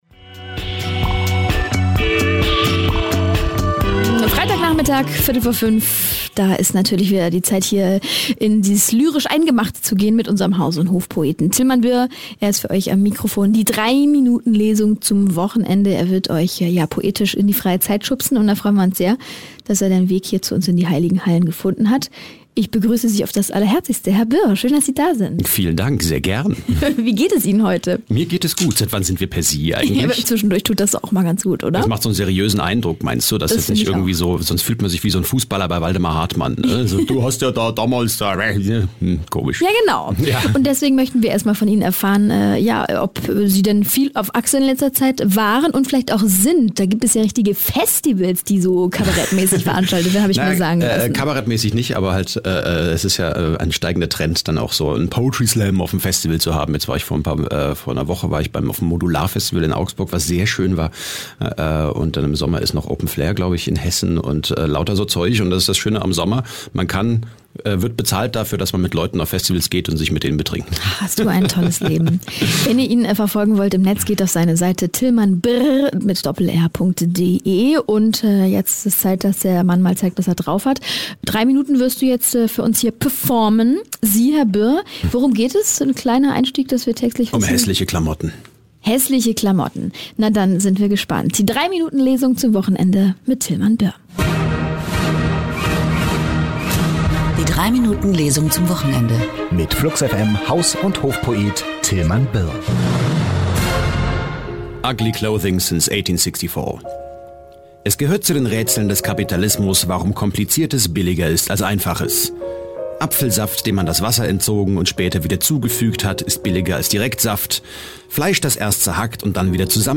3-Minuten-Lesung